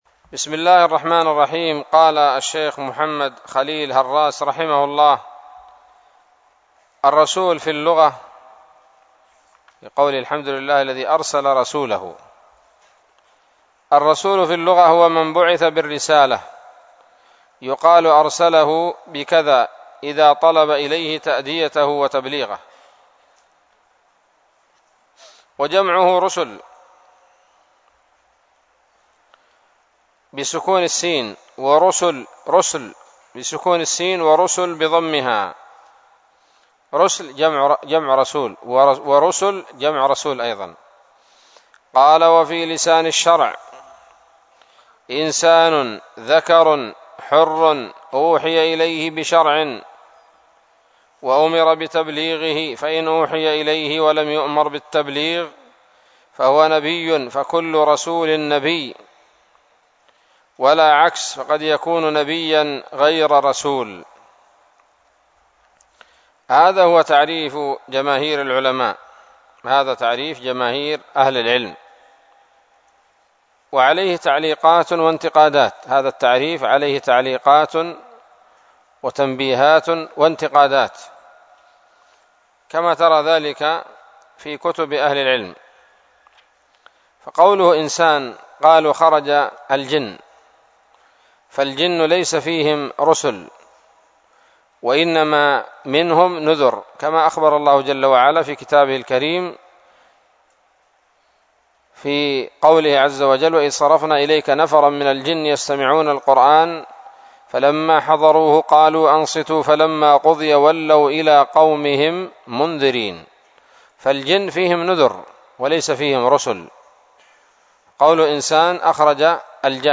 الدرس العاشر من شرح العقيدة الواسطية للهراس